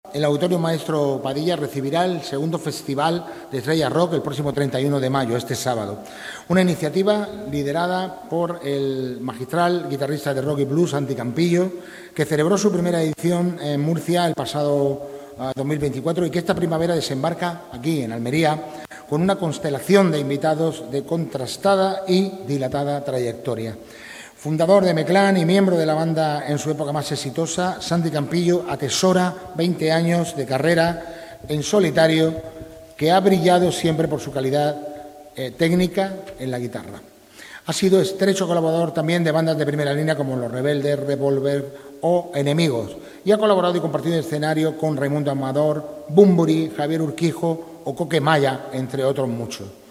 DIEGO-CRUZ-CONCEJAL-DE-CULTURA-FESTIVAL-ESTRELLAS-DEL-ROCK.mp3